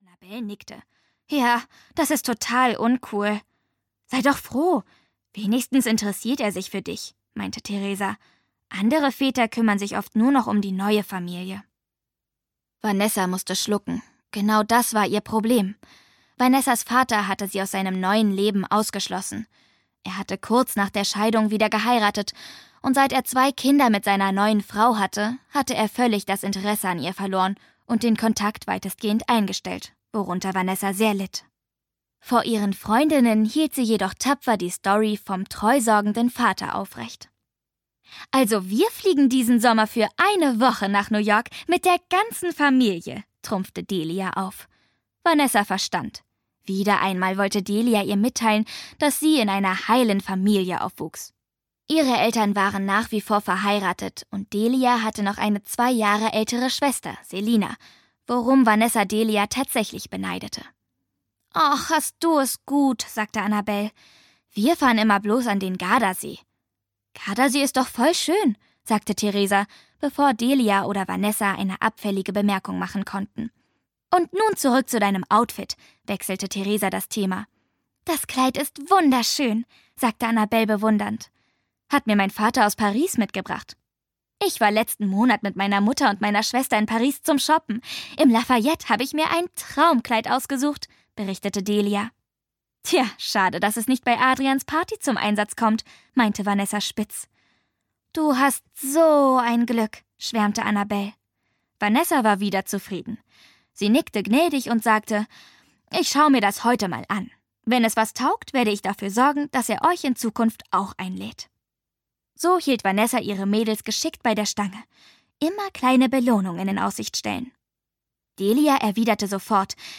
Best Friends Forever: Luca & Vanessa: Plötzlich Schwestern! - Hortense Ullrich - Hörbuch